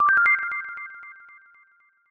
snd_ominous_cancel.wav